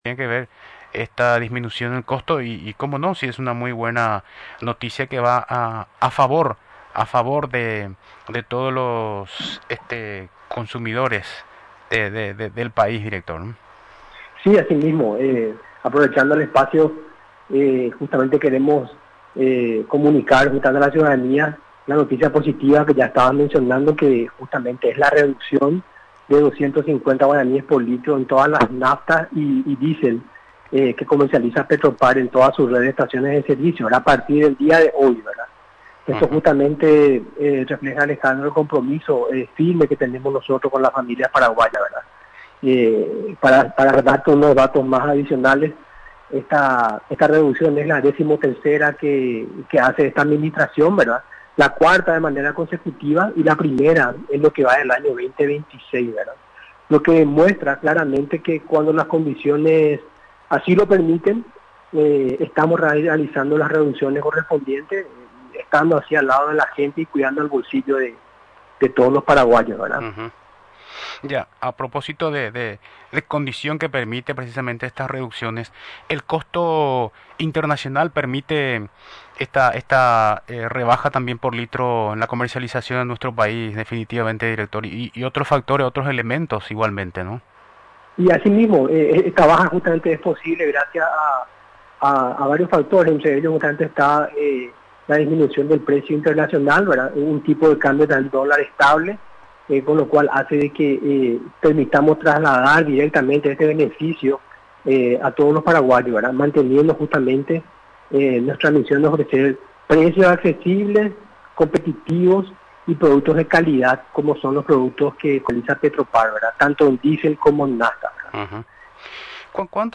En comunicación con Radio Nacional del Paraguay